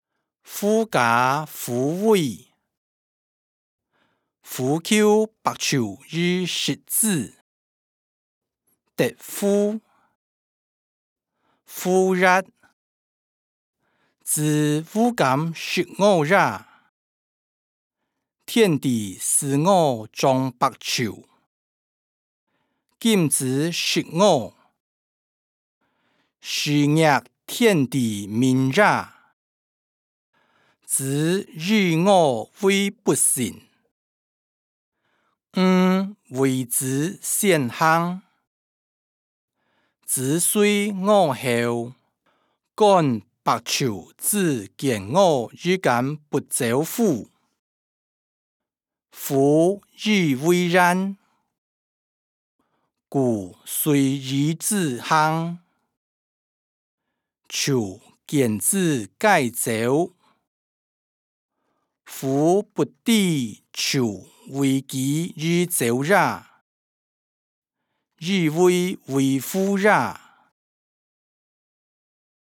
歷代散文-狐假虎威音檔(海陸腔)